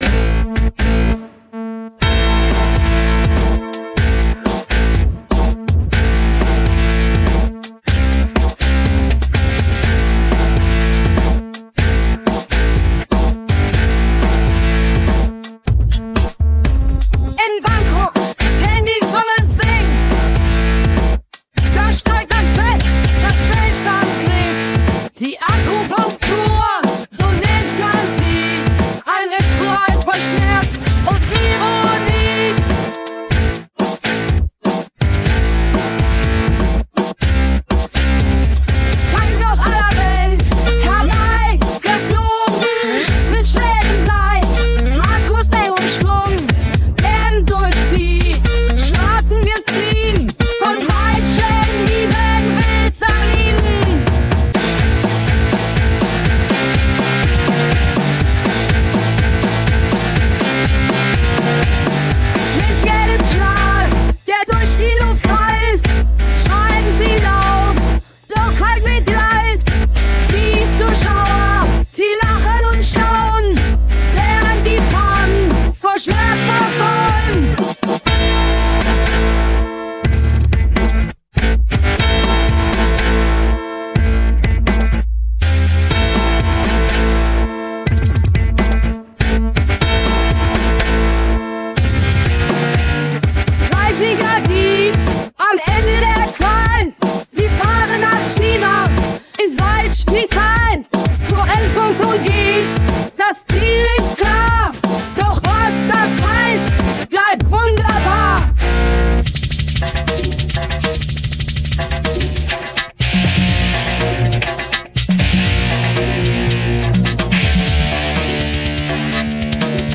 Projekt: KI-unterstütztes Punkalbum